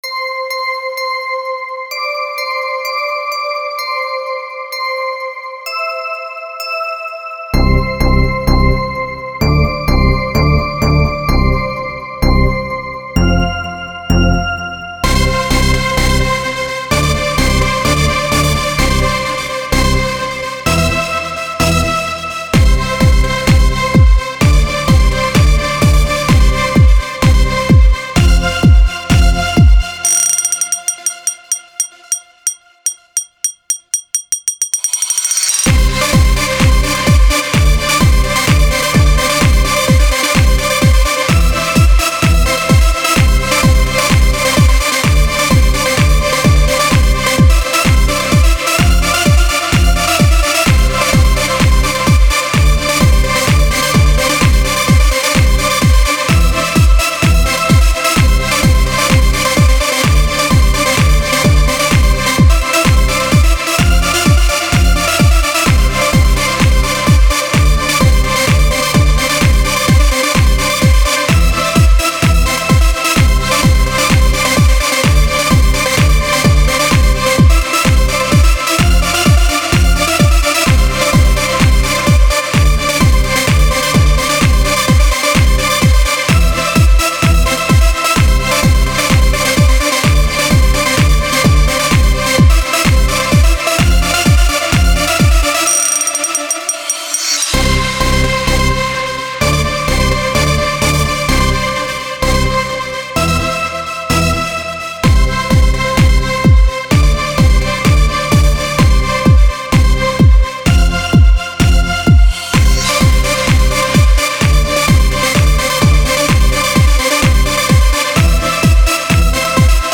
Ситуация следующая: Создал клубный трек в ФЛке, сделан правда только эквалайзинг и наложен реверб, но реверб на на все звуки. Суть в том что в наушниках телефона с вк проигрывателя громкость нормальная, в колонках компьютера тоже нормально всё, а на больших колонках в баре попробовали поставить, как-то тихо очень.